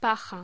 Locución: Paja
Sonidos: Hostelería